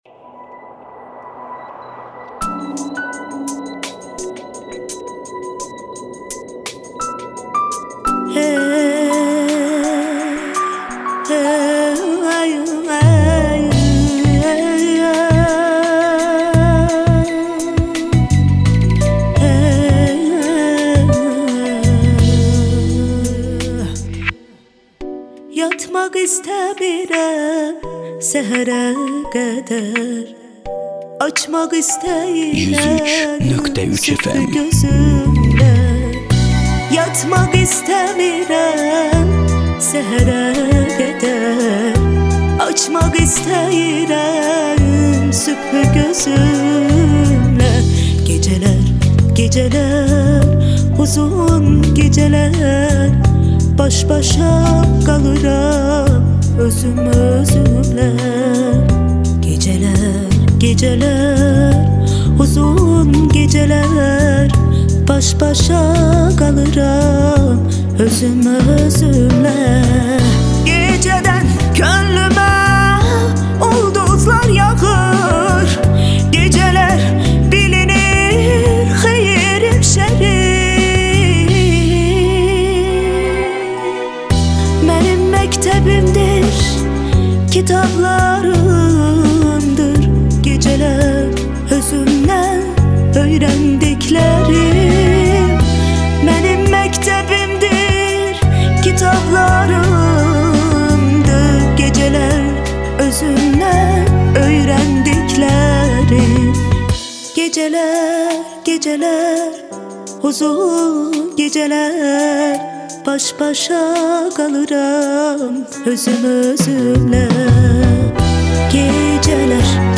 azeri